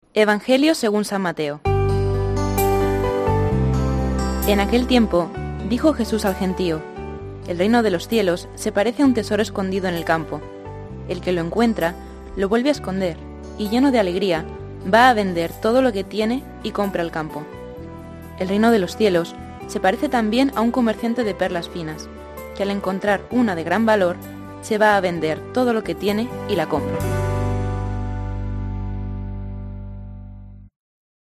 Lectura del santo Evangelio según san Mateo 13,44-46